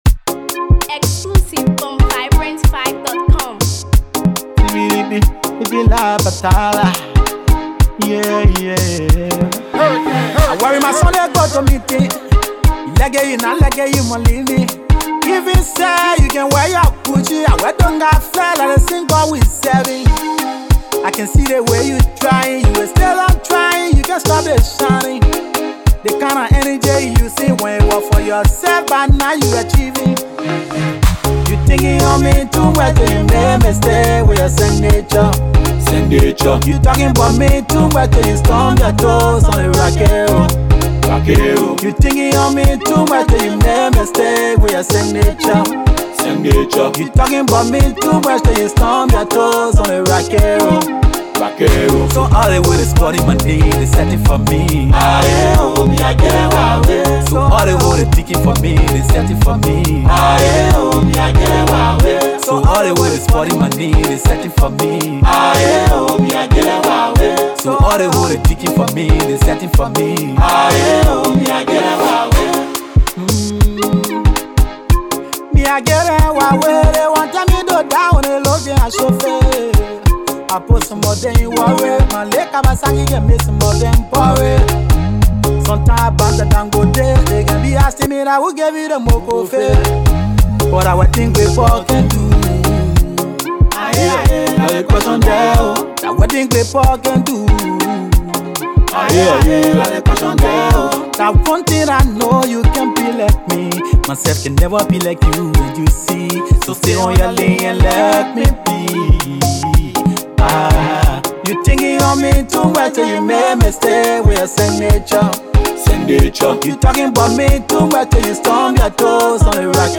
An emerging Liberian cultural and traditional singer
This sounds speaks of the raw culture of liberian music.